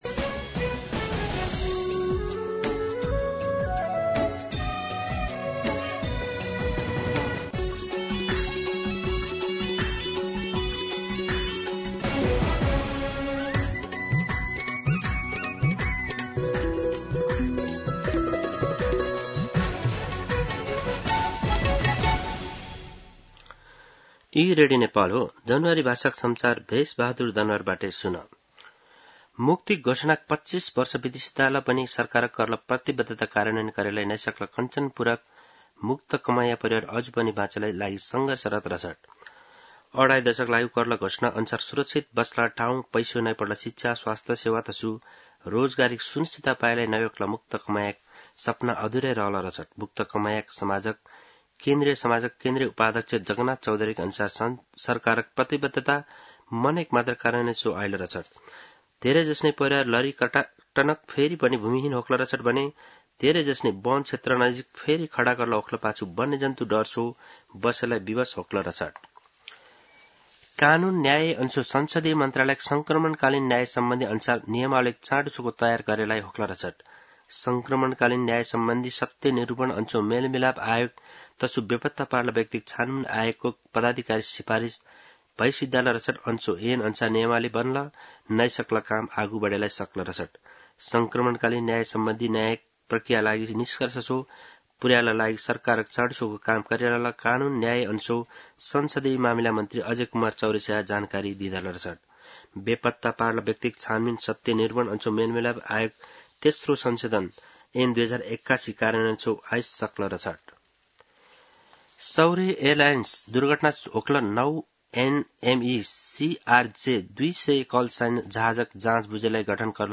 दनुवार भाषामा समाचार : ३ साउन , २०८२